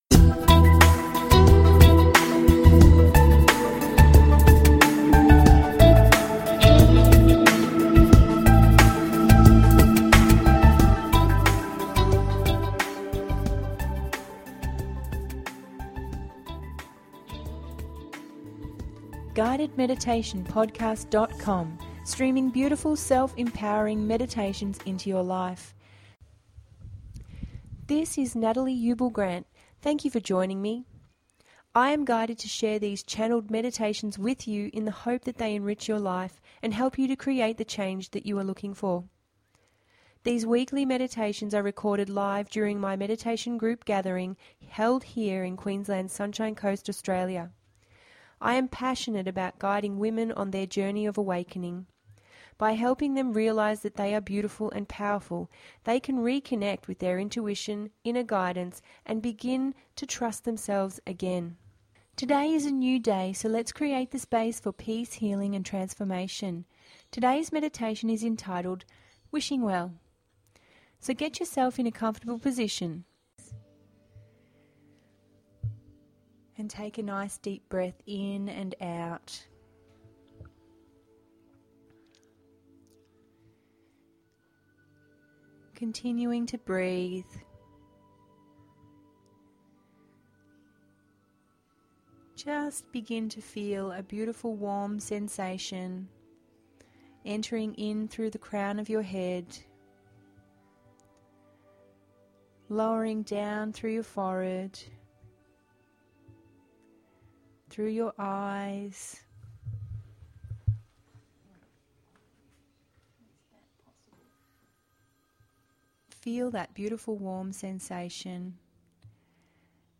Here is this weeks Guided Meditation, entitled Wishing Well.